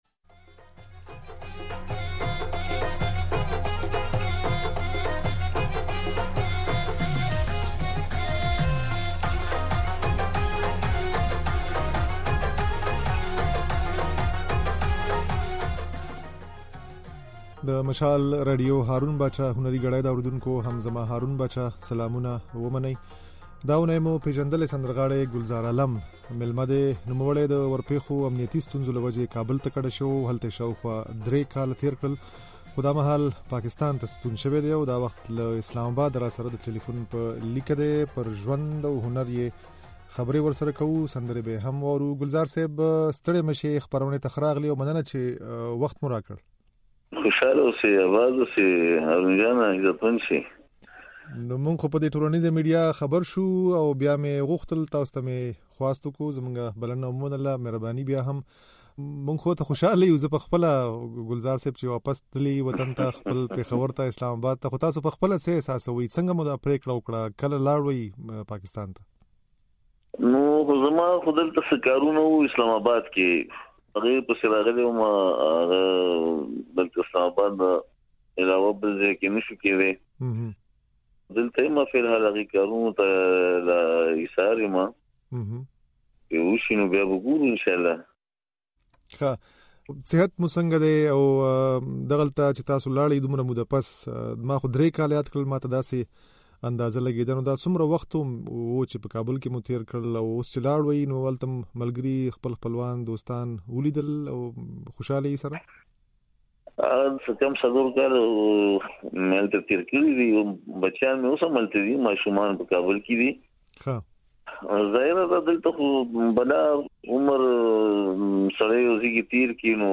دا اوونۍ مو "هارون باچا هنري ګړۍ" خپرونې ته پېژندلی سندرغاړی ګلزار عالم مېلمه کړی وو.
د ګلزار عالم دا خبرې او ځينې سندرې يې د غږ په ځای کې اورېدای شئ.